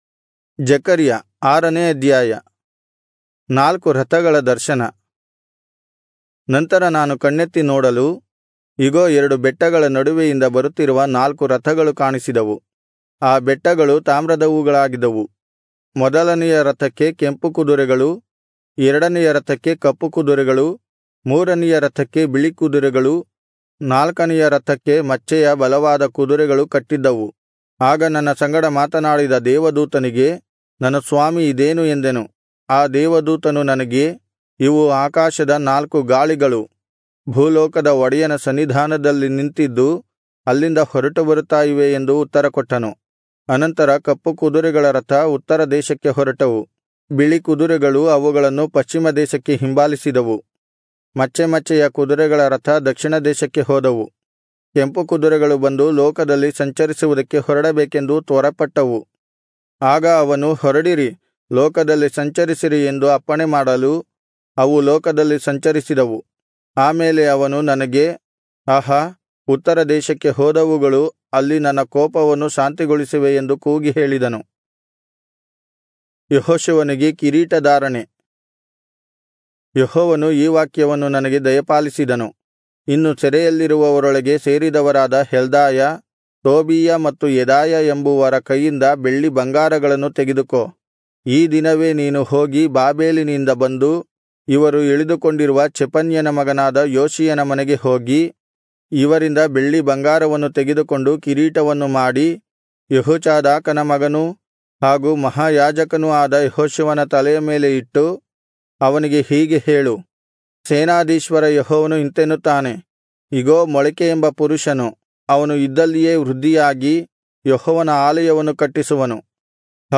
Kannada Audio Bible - Zechariah 11 in Irvkn bible version